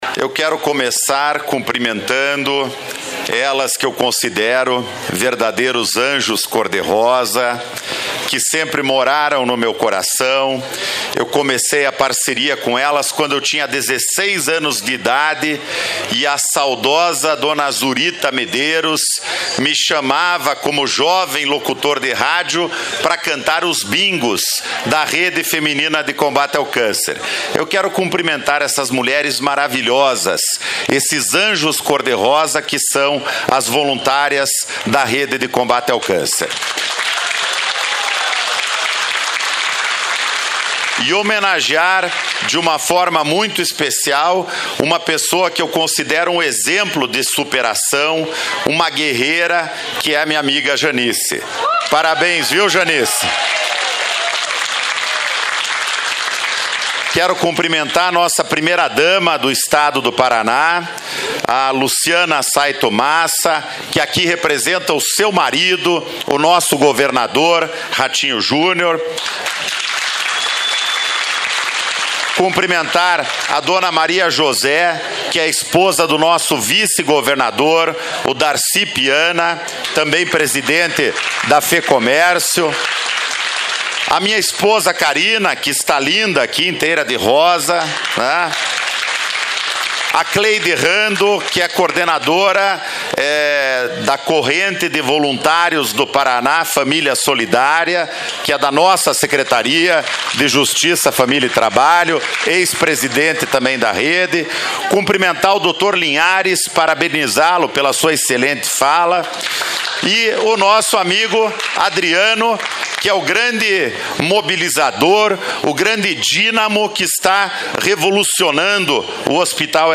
Secretário Ney Leprevost faz discuso na abertura do outubro rosa no Hospital Erasto Gaertner
DISCURSO NEY.mp3